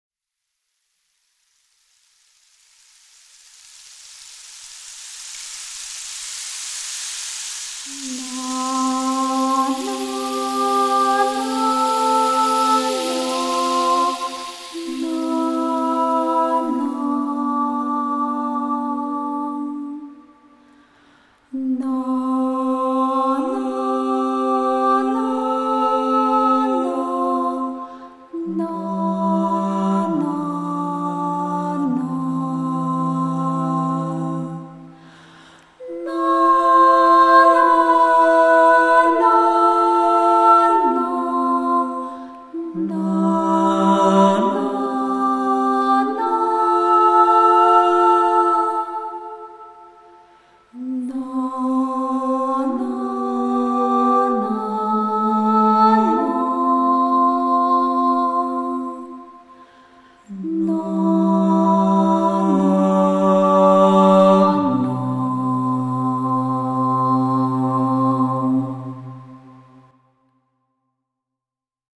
El audio-libro está compuesto por 31 páginas en formato Pdf y 18 pistas de locución y música.